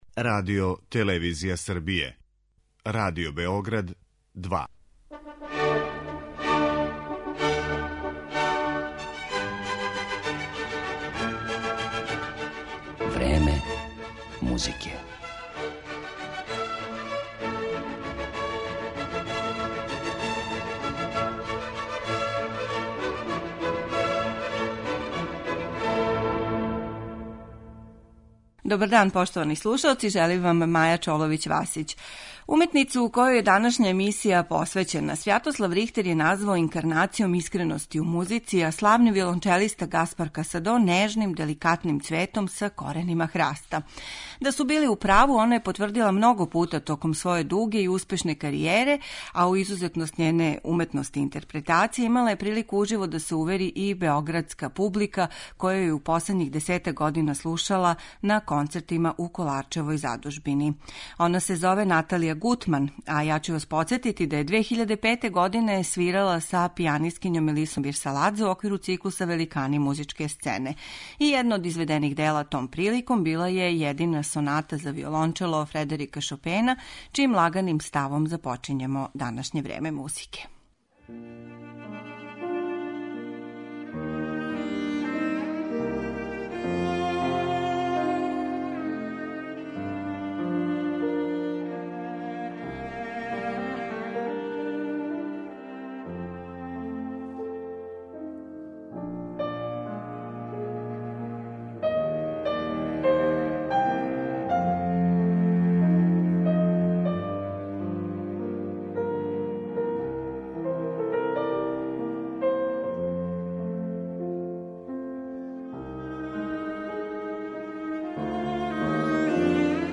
виолончелисткињу